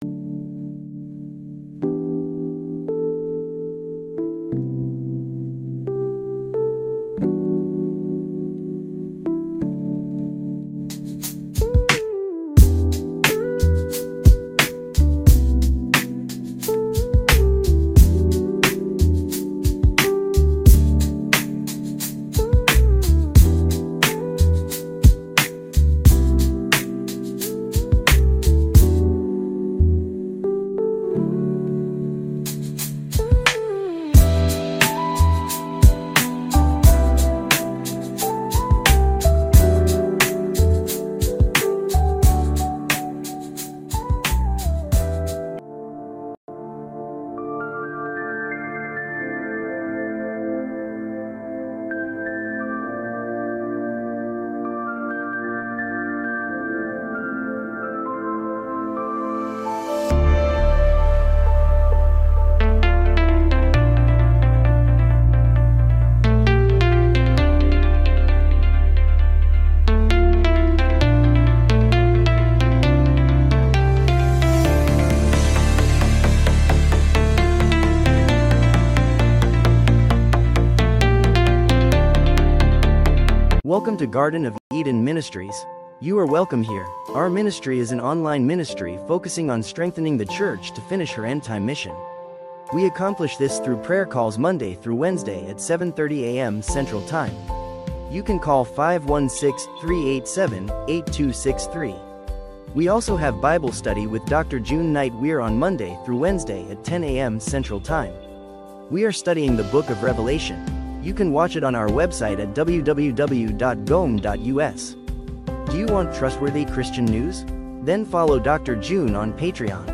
Sermons | Garden of Eden Ministries